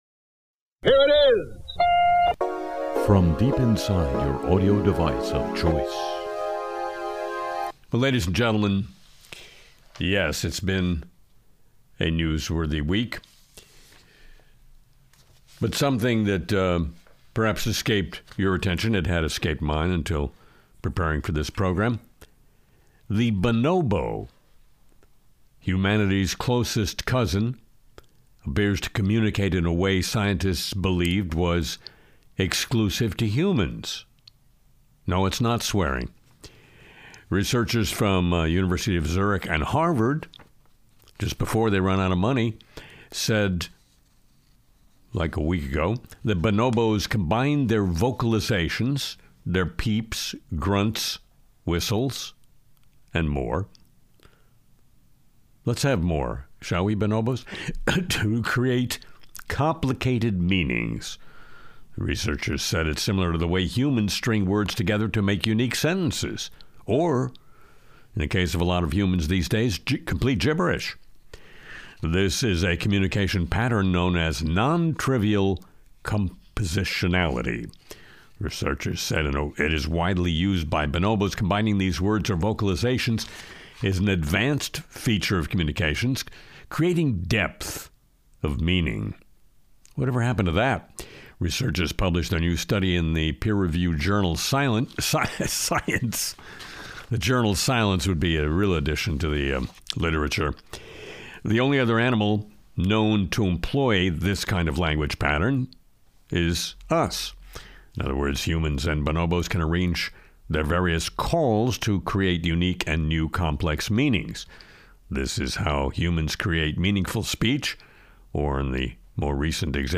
Music & Segments